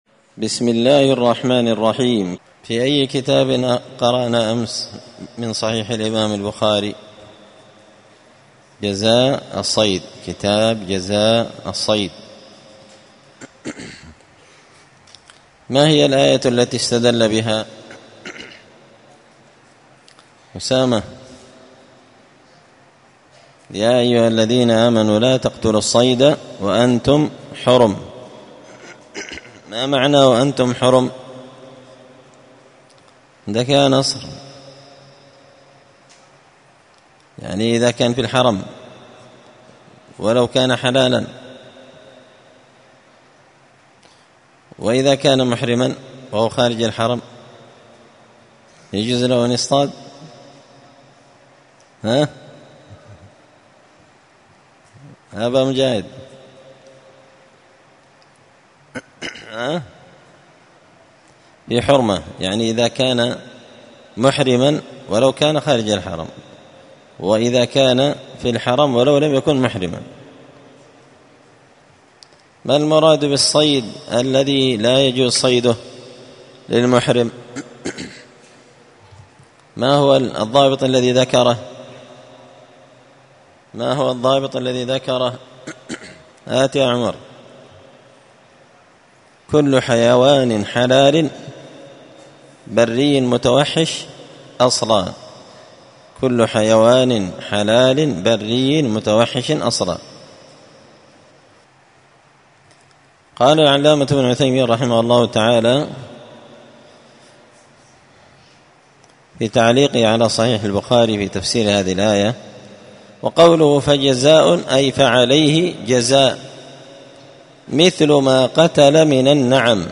الأثنين 19 صفر 1445 هــــ | 5-كتاب جزاء الصيد، الدروس، شرح صحيح البخاري | شارك بتعليقك | 24 المشاهدات
مسجد الفرقان قشن المهرة اليمن